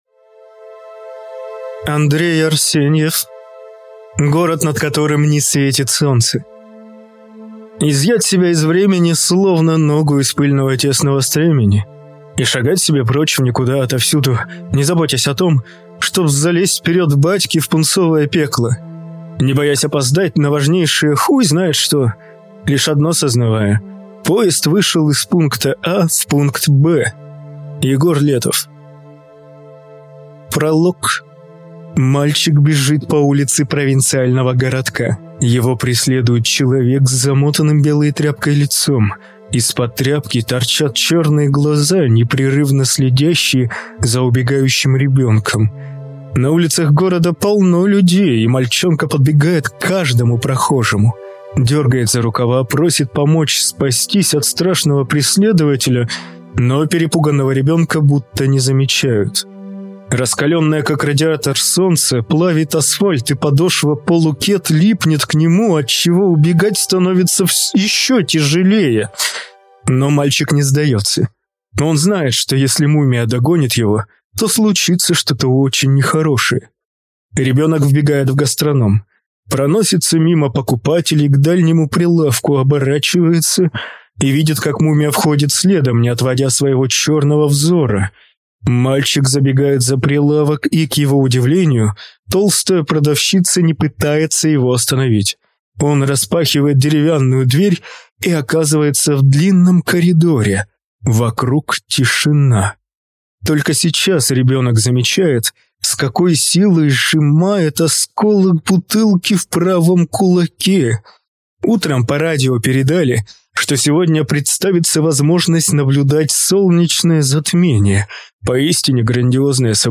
Аудиокнига Город, над которым не светит солнце | Библиотека аудиокниг